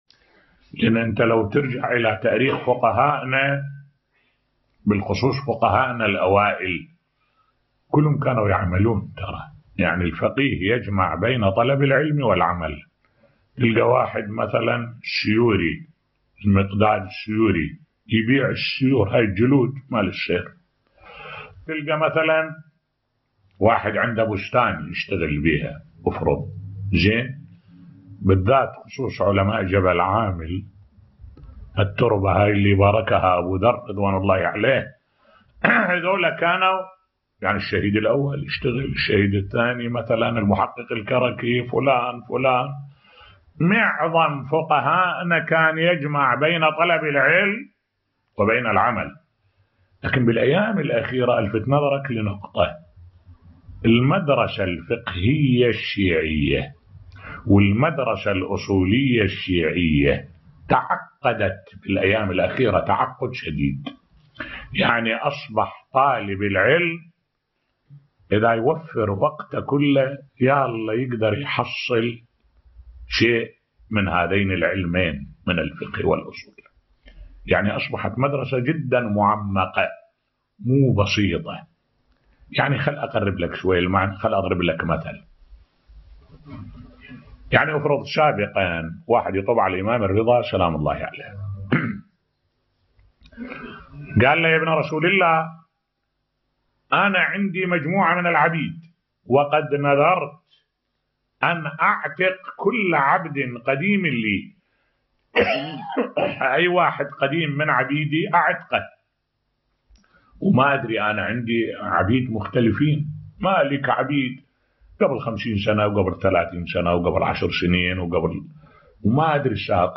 ملف صوتی الفقهاء الاوائل جمعوا بين طلب العلم و العمل بصوت الشيخ الدكتور أحمد الوائلي